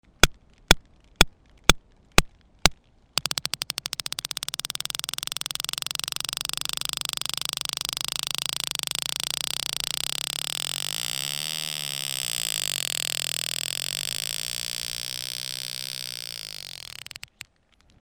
На этой странице собраны их уникальные вокализации: от низкочастотных стонов до резких щелчков эхолокации.
Звук кашалота направленные щелчки от 100 Гц до 30 кГц